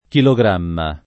chilogramma [ kilo g r # mma ]